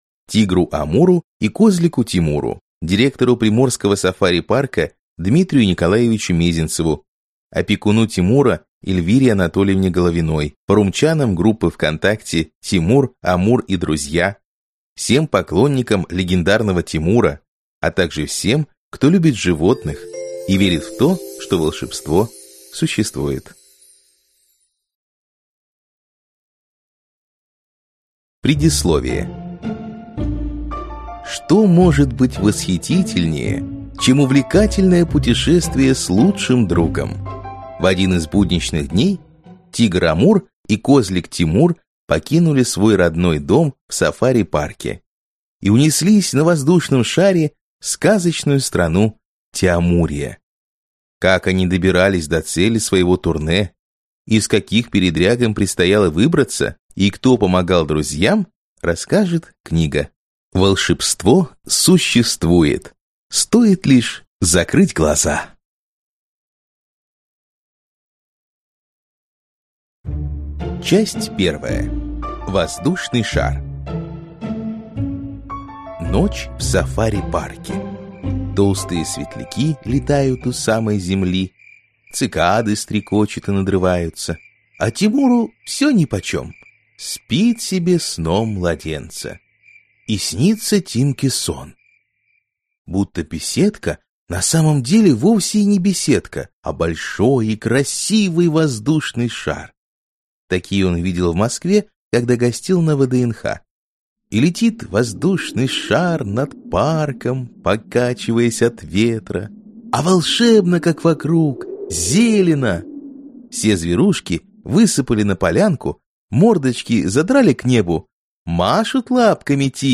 Аудиокнига Путешествие в Тиамурию | Библиотека аудиокниг